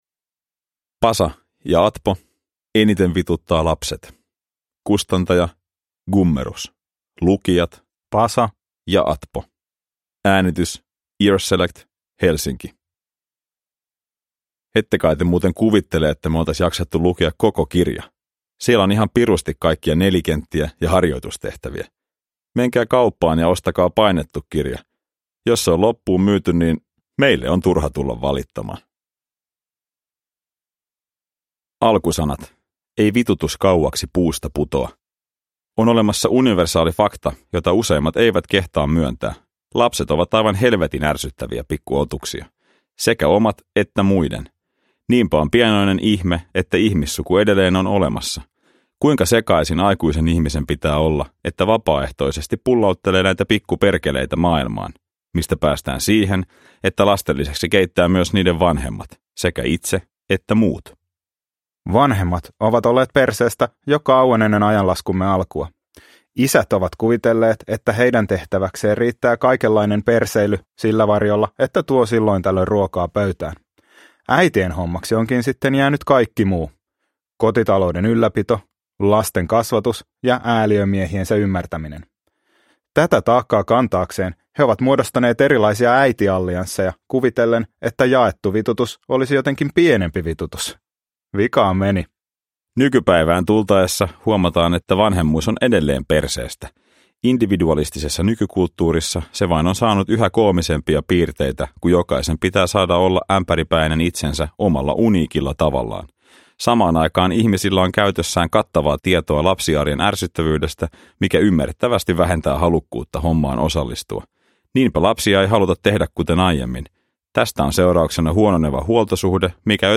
Eniten vituttaa lapset – Ljudbok